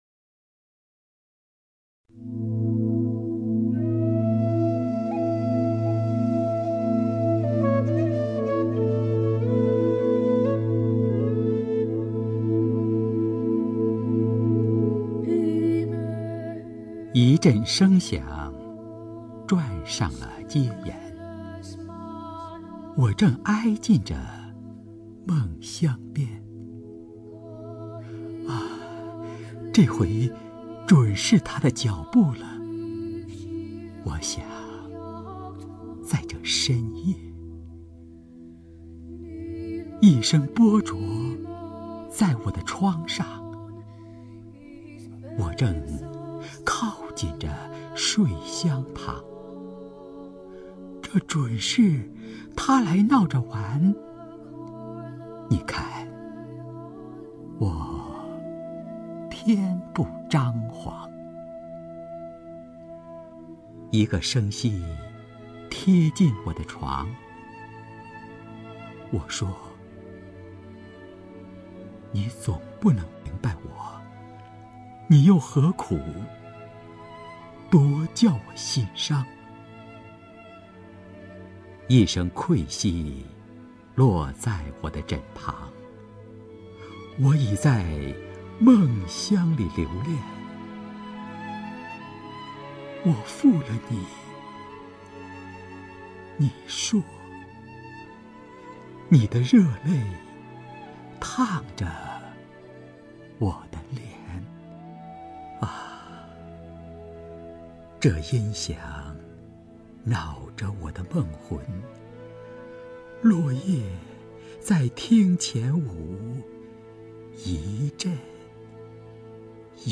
刘纪宏朗诵：《落叶小唱》(徐志摩)
名家朗诵欣赏 刘纪宏 目录